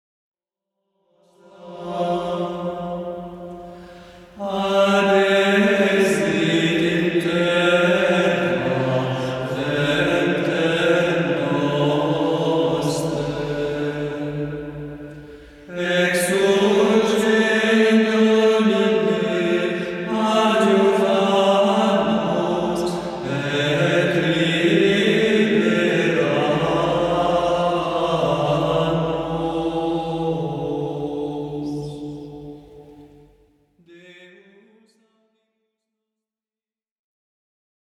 Grégorien/Monastique (70)